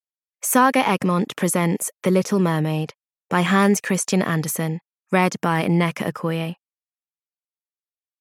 The Little Mermaid (EN) audiokniha
Ukázka z knihy